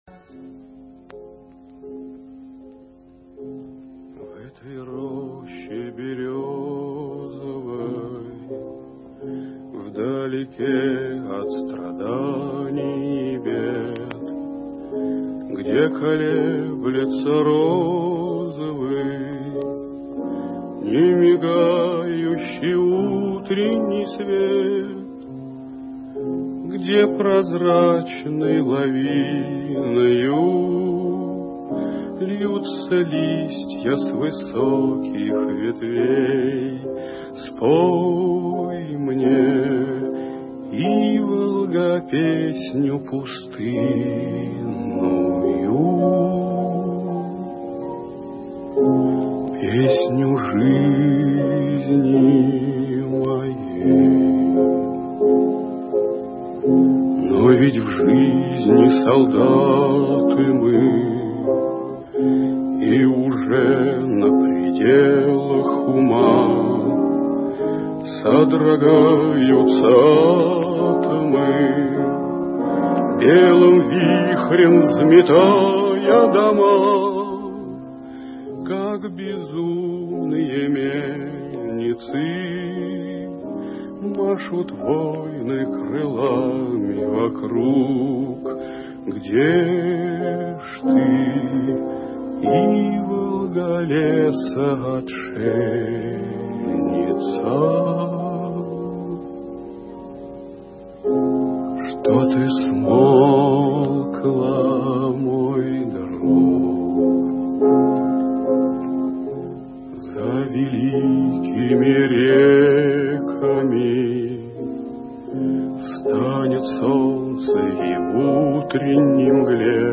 Он садится за пианино и поёт в полумраке песню своей юности.